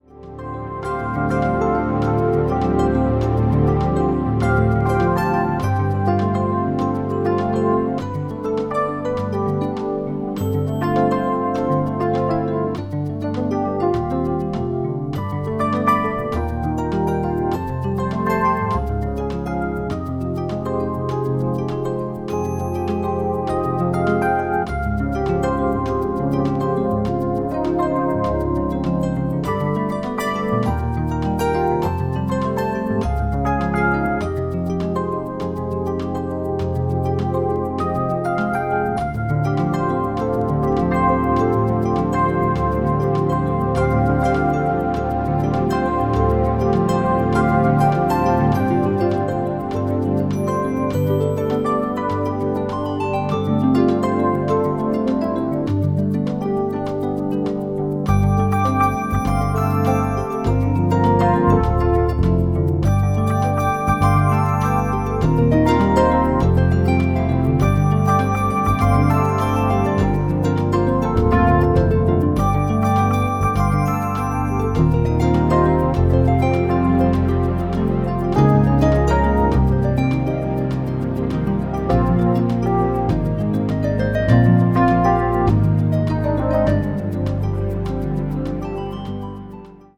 media : EX/EX-(わずかにチリノイズが入る箇所あり,B1:再生音に影響ない薄い擦れ一部あり)
ambient   contemporary jazz   modern classical   new age